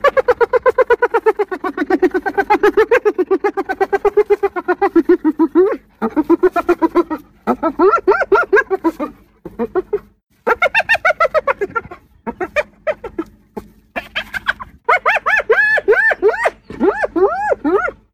Risada Homem Imitando Hiena
Áudio de um homem imitando a risada de uma hiena.
risada-homem-imitando-hiena.mp3